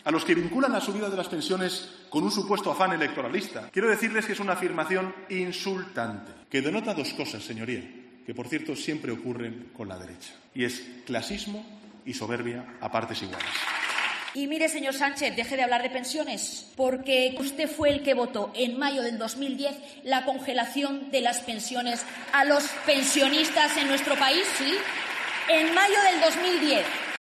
"Es tiempo de cambiar y como usted no cambia, los españoles cambiarán a su gobernante", ha sido la interpelación de la portavoz del Partido Popular en el Congreso, Cuca Gamarra a las acusaciones de Sánchez.